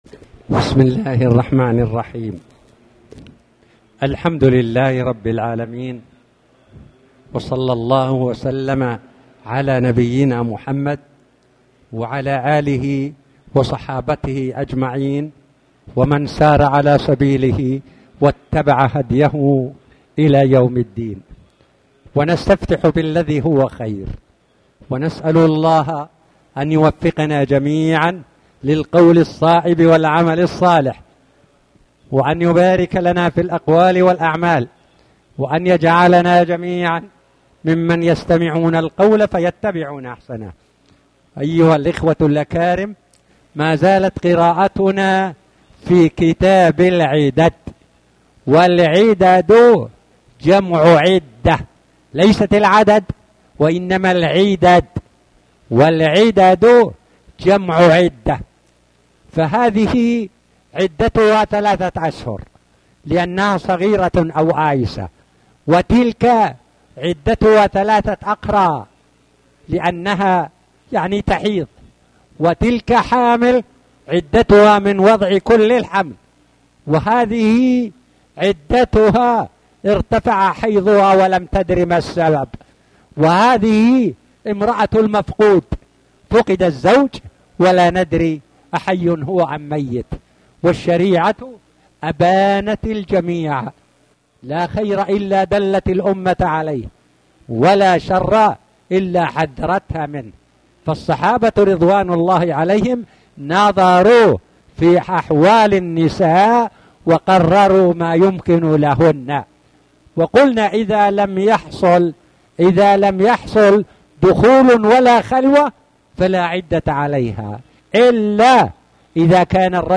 تاريخ النشر ١٤ محرم ١٤٣٩ هـ المكان: المسجد الحرام الشيخ